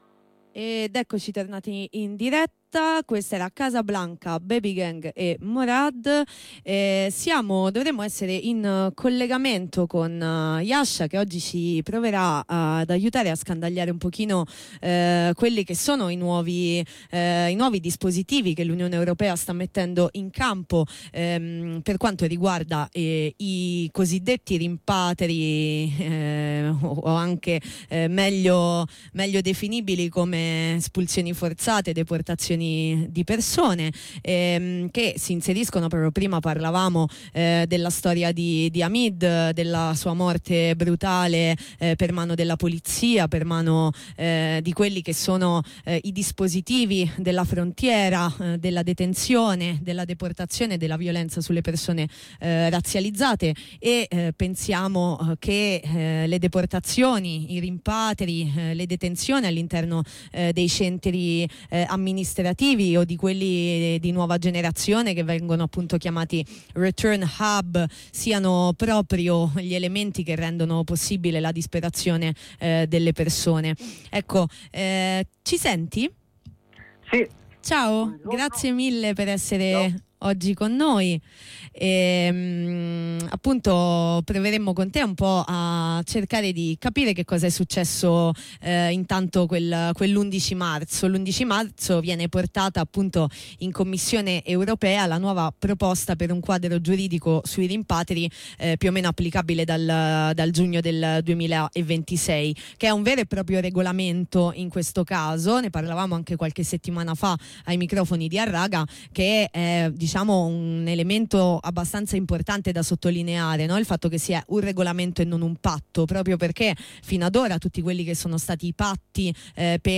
Ne parliamo, ai microfoni di Harraga , in onda su Radio Blackout